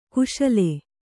♪ kuśale